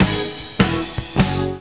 LIVE at Club24 - in JAPANESE -